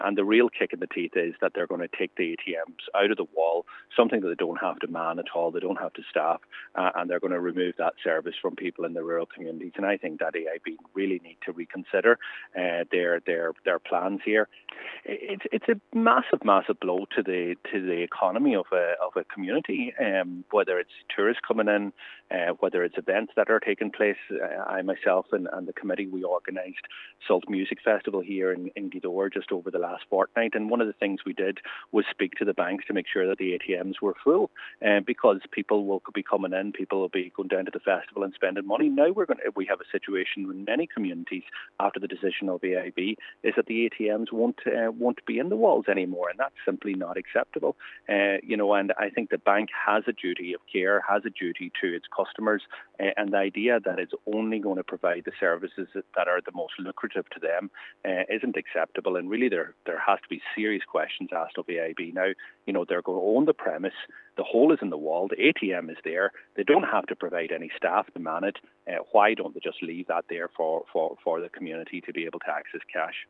Donegal Deputy Pearse Doherty says the removal of the ATMs is a major blow and has called for the bank to reconsider..